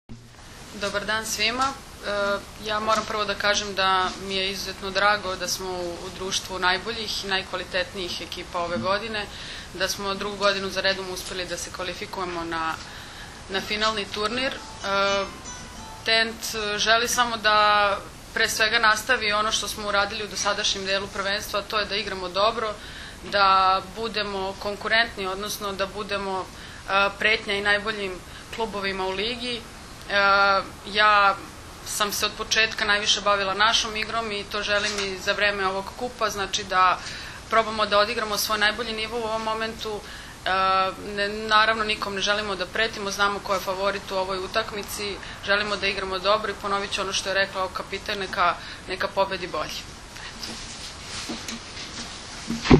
U prostorijama Odbojkaškog saveza Srbije danas je održana konferencija za novinare povodom Finalnog turnira 48. Kupa Srbije u konkurenciji odbojkašica, koji će se u subotu i nedelju odigrati u dvorani “Park” u Staroj Pazovi.